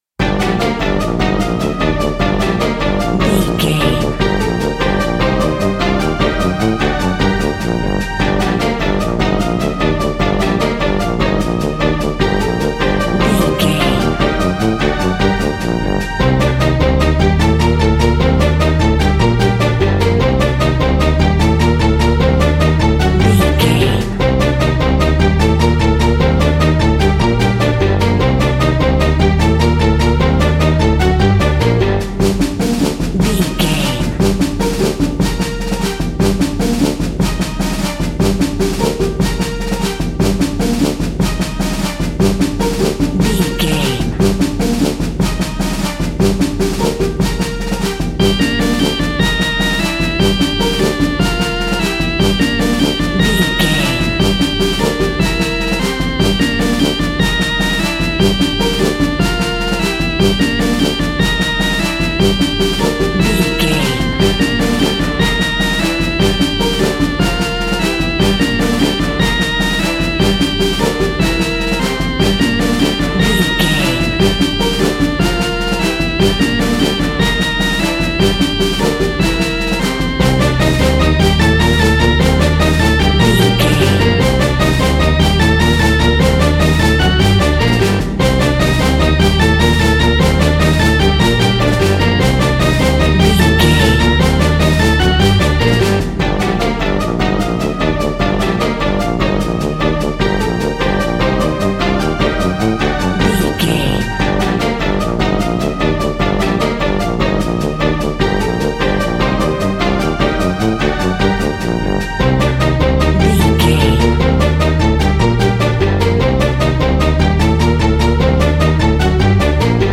A great piece of royalty free music
In-crescendo
Thriller
Aeolian/Minor
Fast
scary
ominous
dark
dramatic
eerie
energetic
brass
synthesiser
drums
strings
horror music